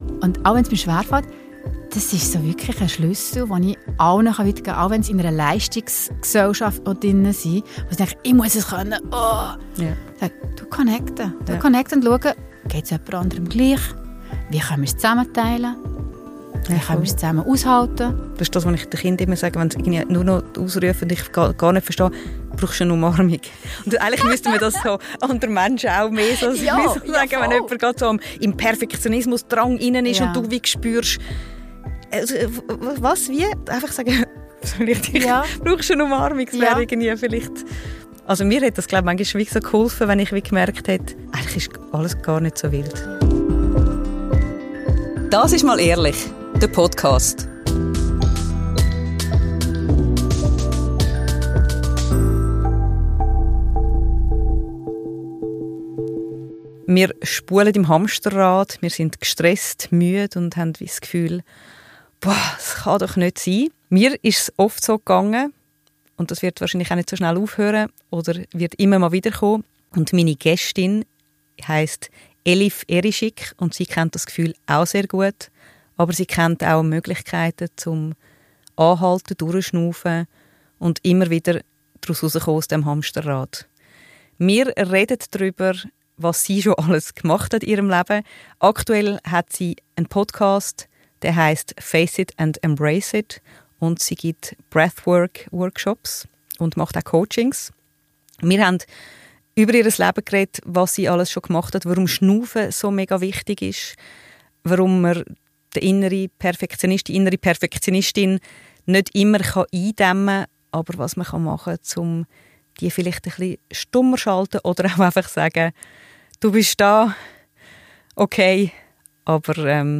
Gespräche rund um Elternsein, persönliche Bedürfnisse und Gesellschaftsthemen.